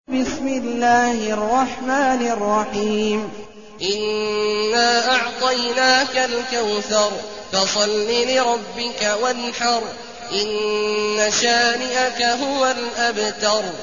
المكان: المسجد النبوي الشيخ: فضيلة الشيخ عبدالله الجهني فضيلة الشيخ عبدالله الجهني الكوثر